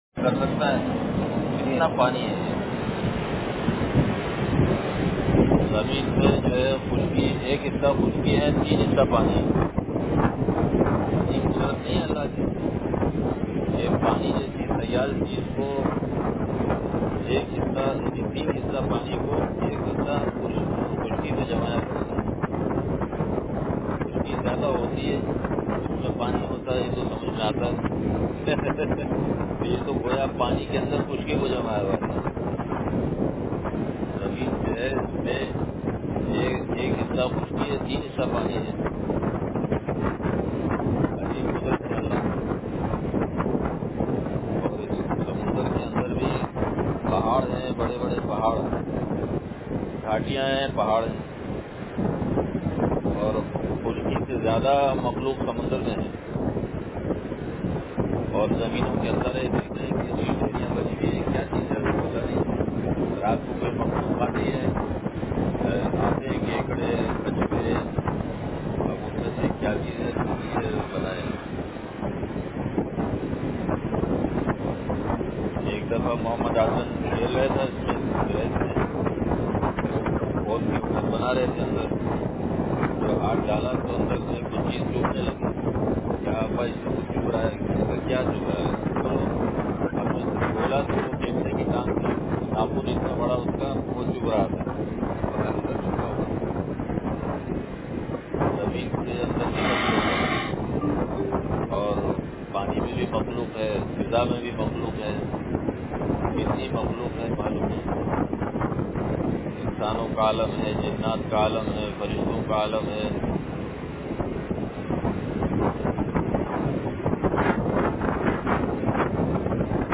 بعد نمازِ فجر مجلسِ ذکر بمقام ساحلِ سمندر